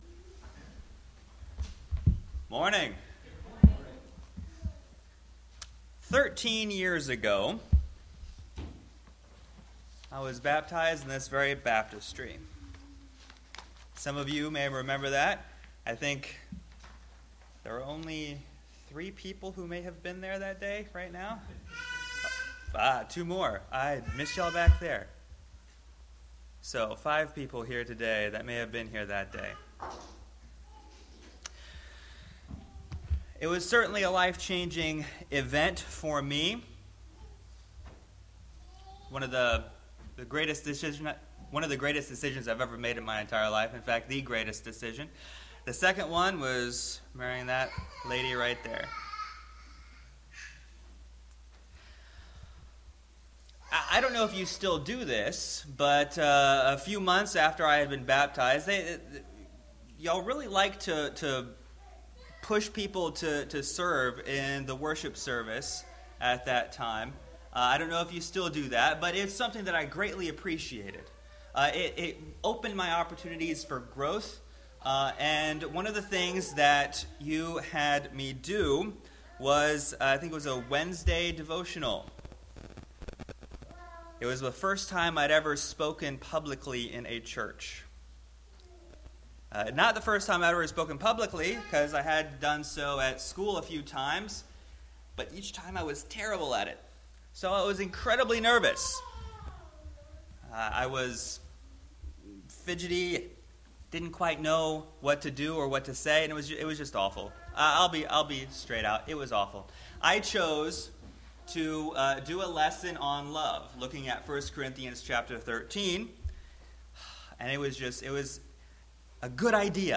AM Sermon
Service Type: AM Worship